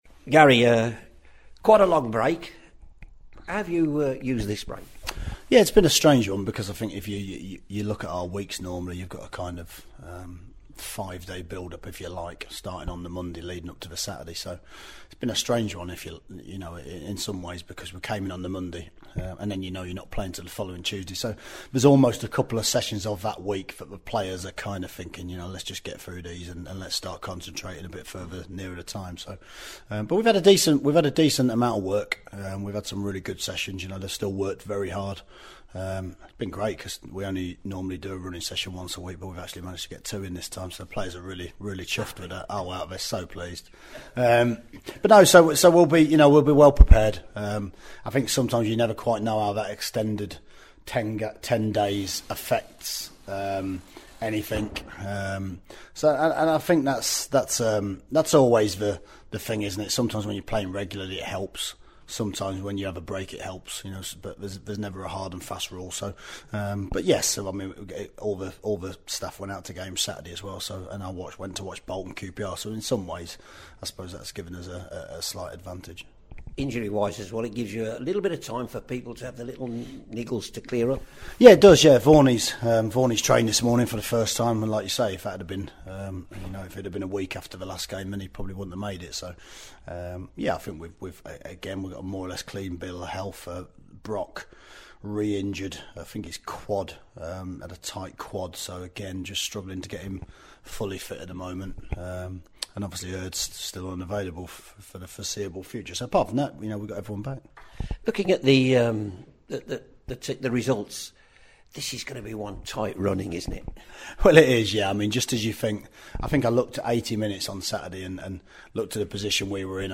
at the Blues Wast Hills training ground